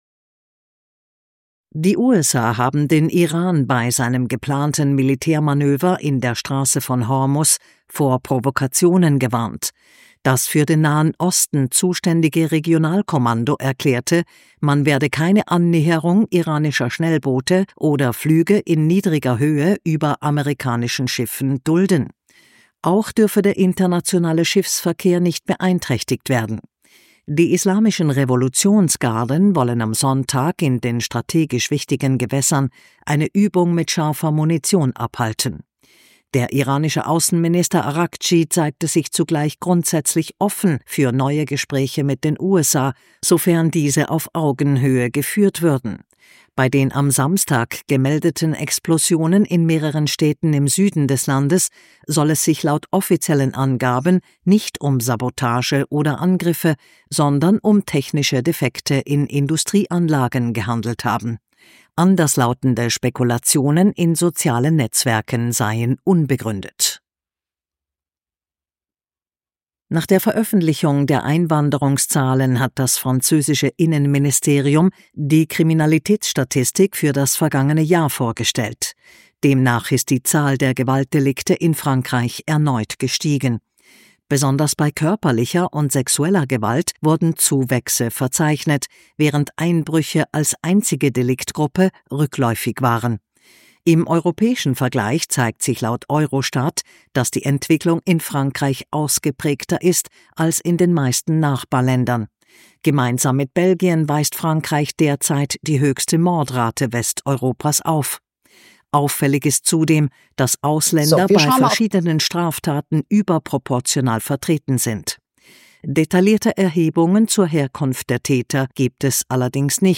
Kontrafunk Sonntagsrunde – Nachrichten vom 1.2.2026